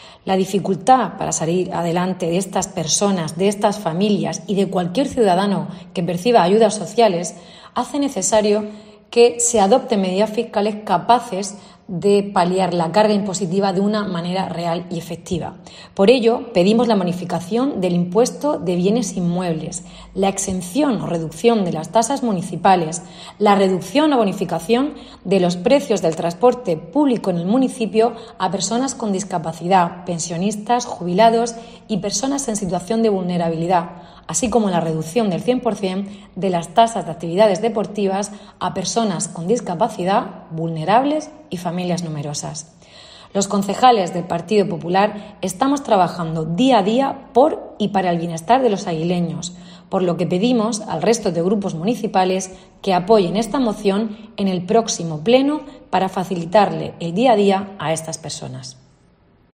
Rosa Soler, concejal del PP en Águilas